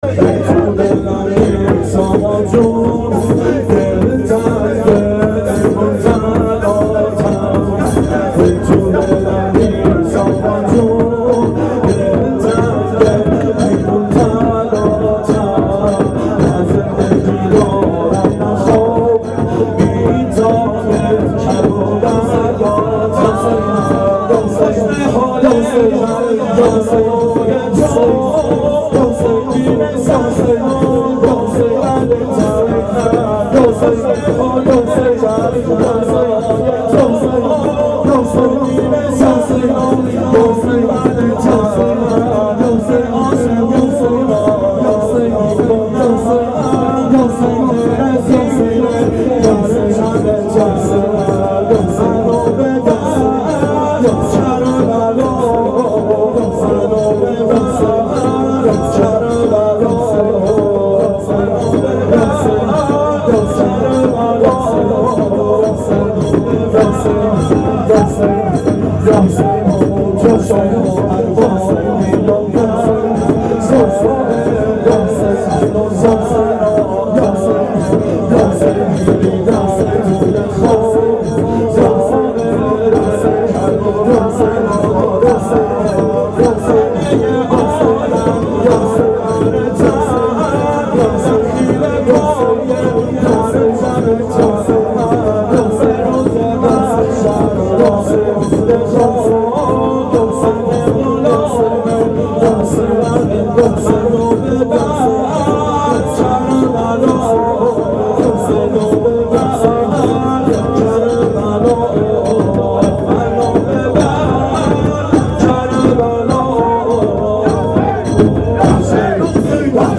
شب عاشورا 92 هیأت عاشقان اباالفضل علیه السلام منارجنبان